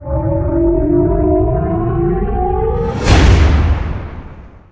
BorgEnterWarp.wav